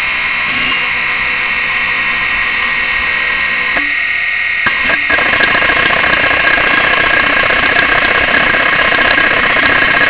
You will find some examples below of sounds that indicate a severe physical problem with a drive.
Here are some sounds of drives that have suffered damage to the heads:
HeadCrash.wav